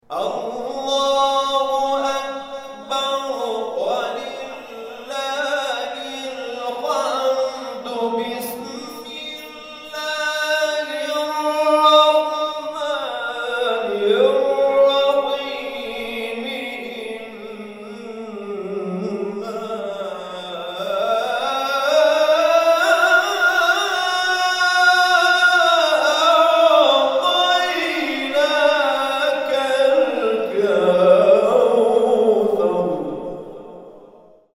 گروه جلسات و محافل: کرسی های تلاوت نفحات القرآن طی هفته گذشته در مساجد احباب الحسین(ع)، عمار یاسر و حضرت ابالفضل(ع) تهران برگزار شد.
در ادامه قطعات تلاوت این کرسی های تلاوت ارائه می شود.